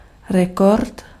Ääntäminen
Ääntäminen Haettu sana löytyi näillä lähdekielillä: ruotsi Käännös Ääninäyte 1. rekord {m} Artikkeli: ett .